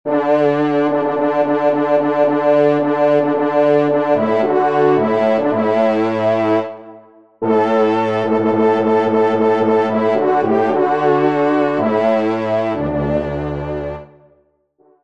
Genre : Musique Religieuse pour  Quatre Trompes ou Cors
Pupitre 4° Trompe